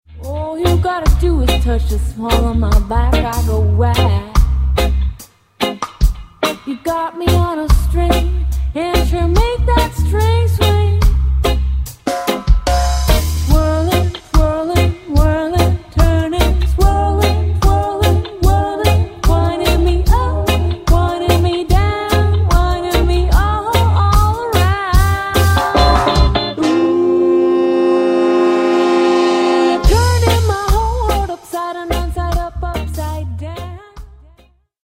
hinzu kommt ein Mix aus zwei Vocals.